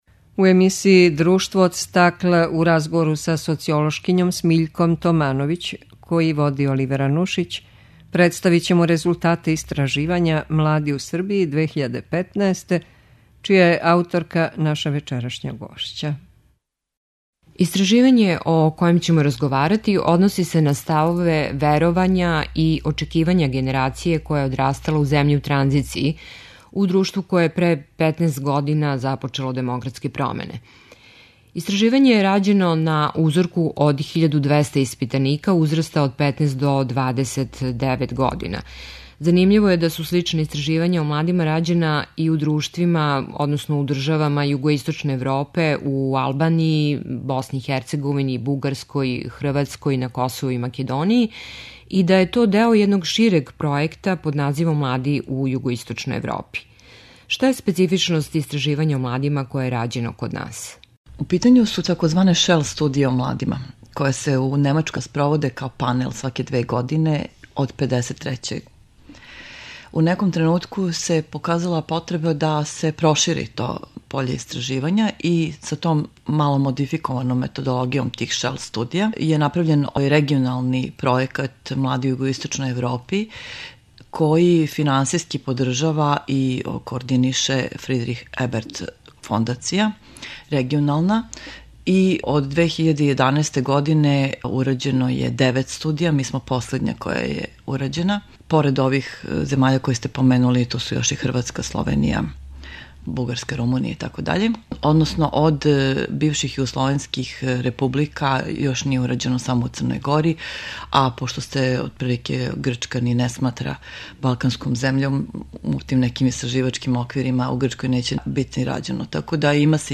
ДРУШТВО ОД СТАКЛА у разговору